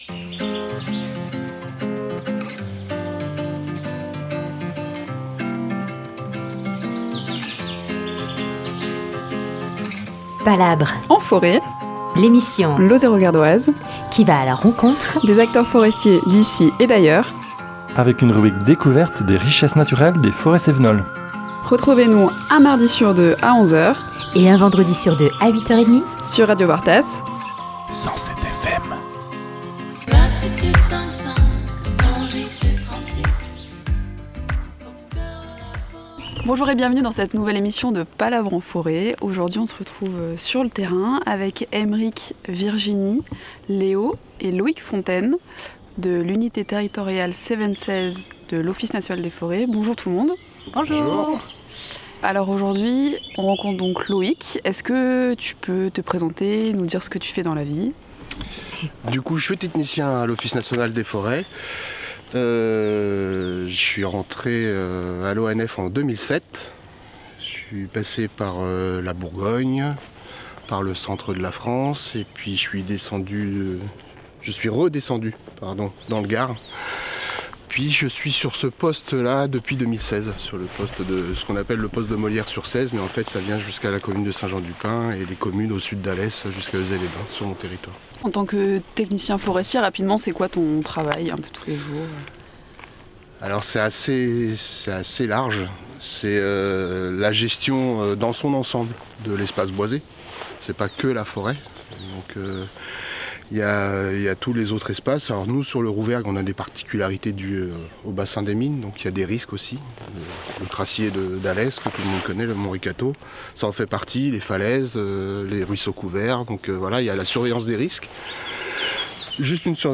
Bienvenue en Forêt domaniale du Rouvergue, sur la commune de Saint-Jean-du-Pin